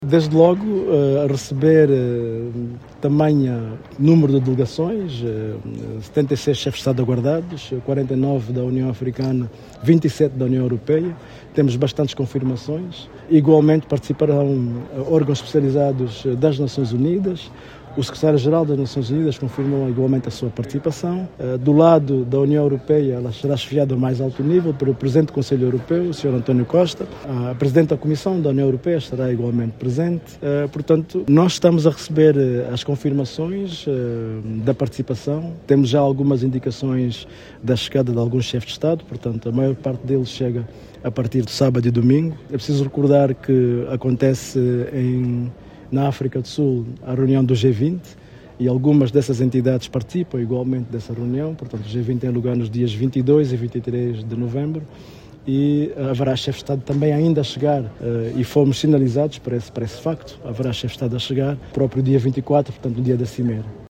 O porta-voz da Cimeira União Europeia/União Africana, Embaixador Jorge Cardoso, afirma que os Chefes de Estado que vão participar na reunião de cúpula começam a chegar no fim de semana.